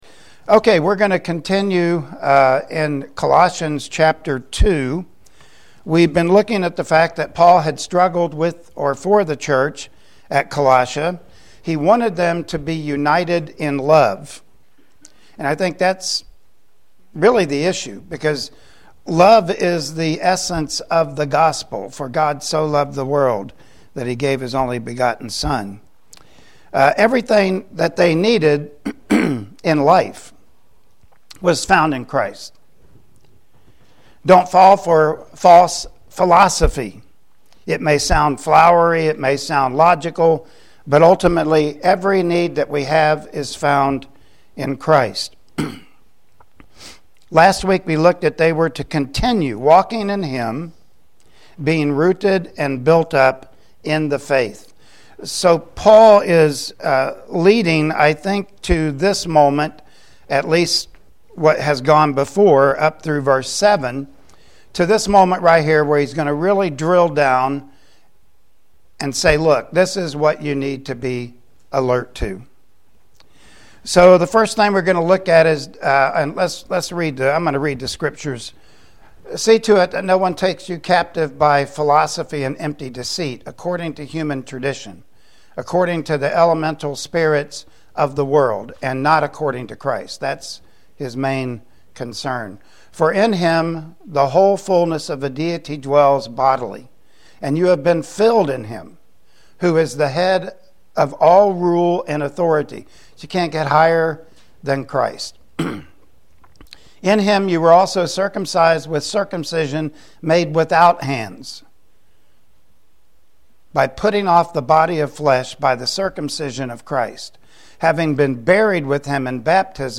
Passage: Colossians 2.8-12 Service Type: Sunday Morning Worship Service Topics